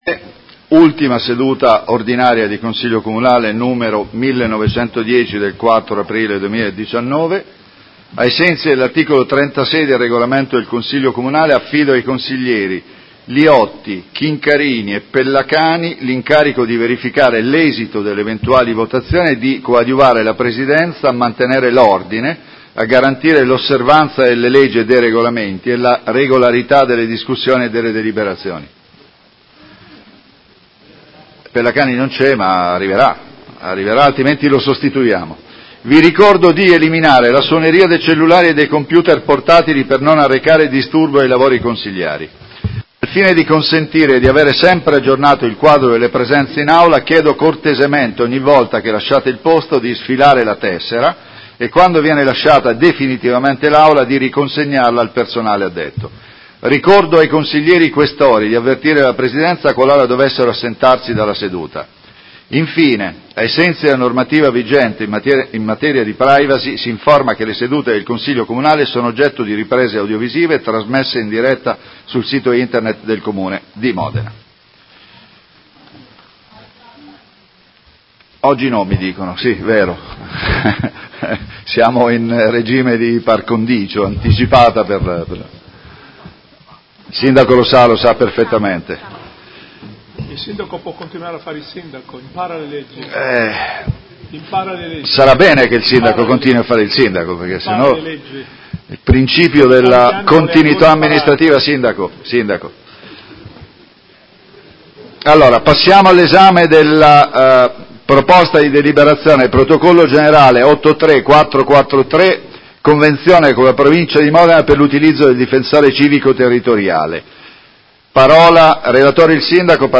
Seduta del 04/04/2019 Come Presidente di turno apre il lavori del Consiglio Comunale.